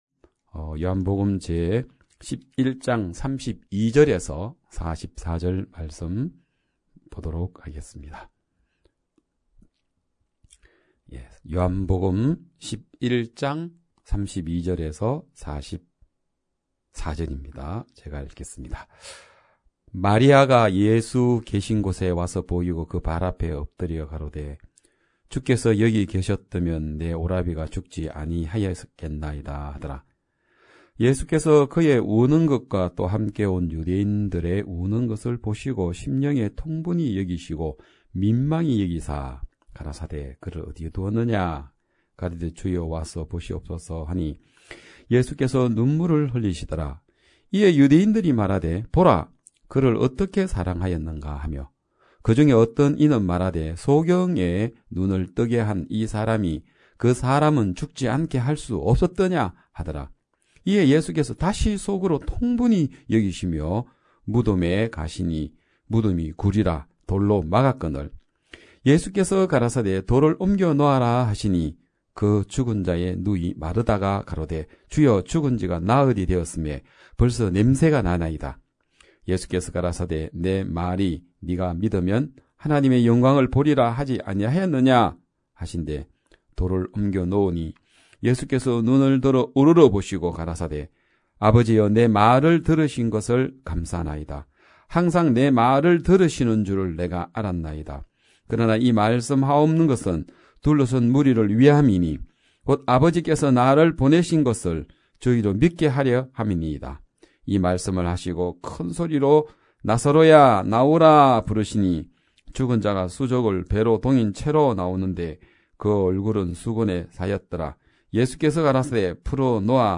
2022년 1월 2일 기쁜소식양천교회 주일오전예배
성도들이 모두 교회에 모여 말씀을 듣는 주일 예배의 설교는, 한 주간 우리 마음을 채웠던 생각을 내려두고 하나님의 말씀으로 가득 채우는 시간입니다.